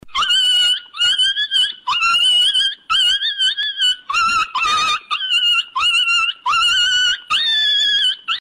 狗狗挨打之后惨叫音效